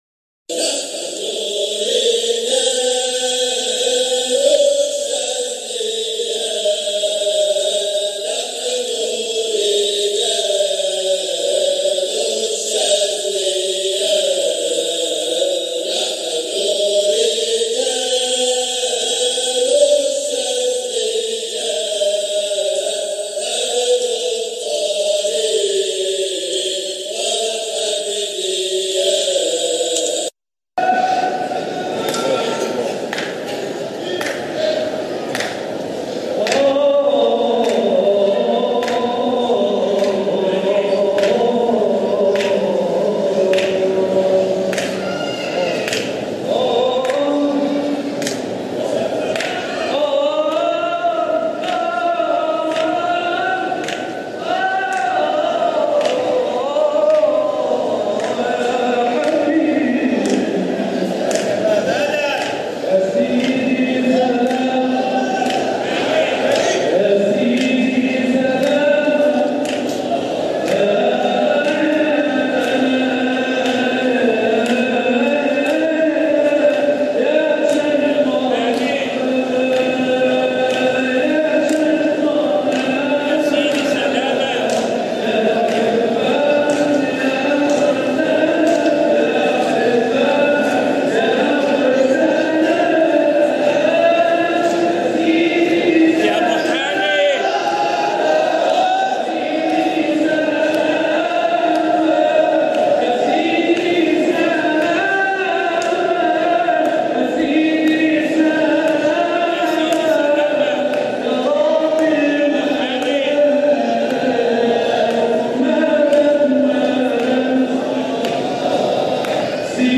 مقاطع من احتفالات ابناء الطريقة الحامدية الشاذلية بمناسباتهم
جزء من حلقة ذكر بمسجد سيدنا احمد البدوى قُدس سره 2017